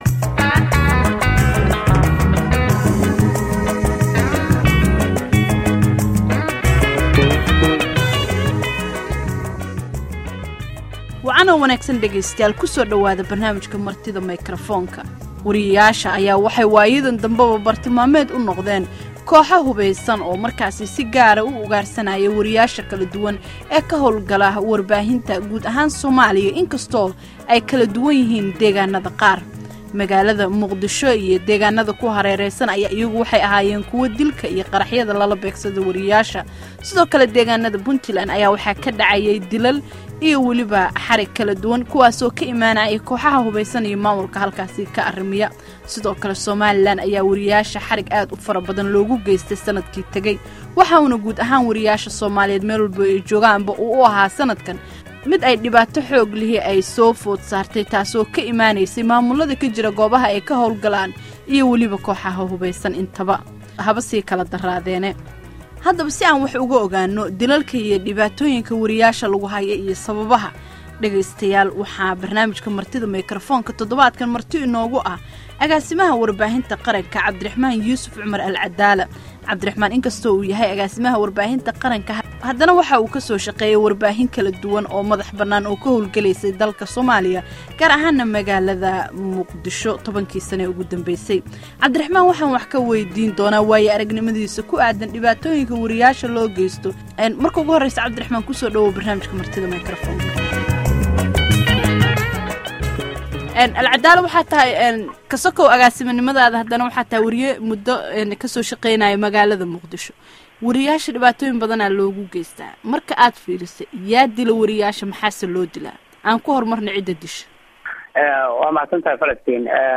Barnaamijka Martida waxaa caawa marti ku ah, C/raxmaan Yuusuf Al-Cadaalla oo ah agaasimaha warbaahinta qaranka oo ka hadlaya cidda geysata dilalka weriyayaasha Soomaaliya.